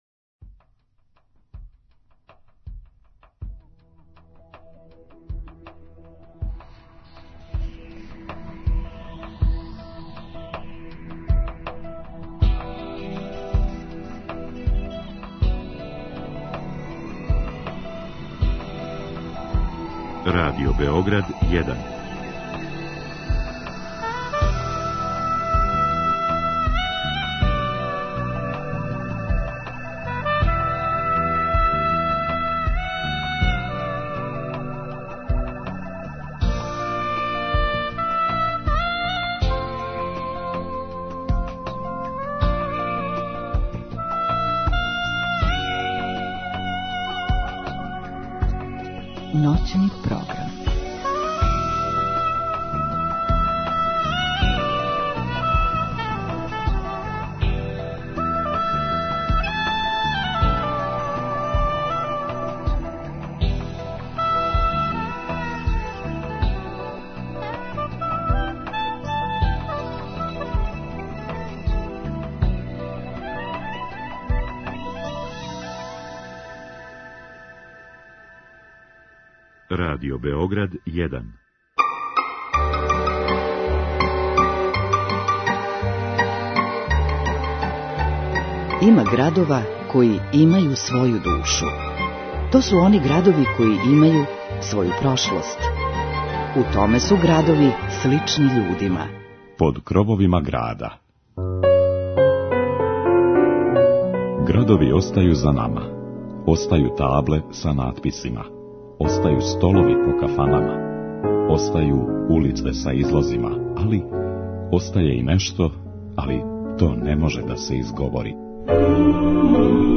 Интересантном причом и музиком, покушаћемо да што боље упознате Крагујевац и људе који су га прославили.